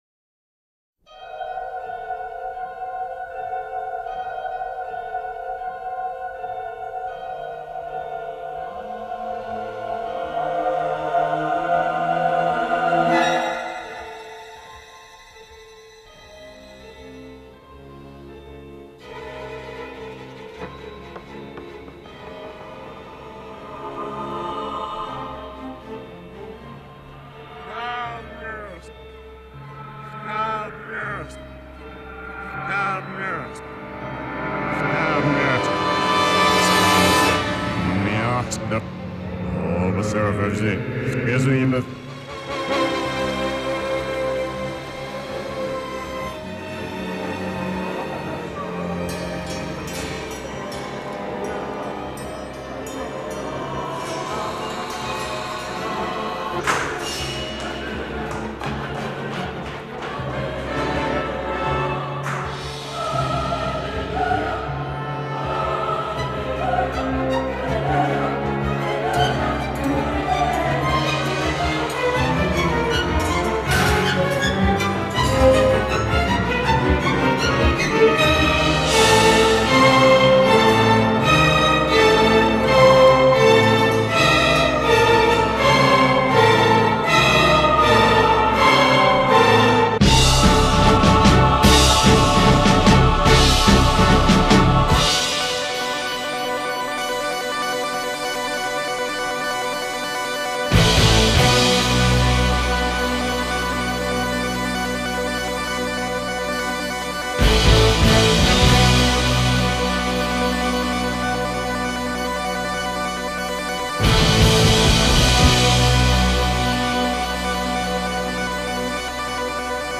Rock Instrumental
موسیقی بی کلام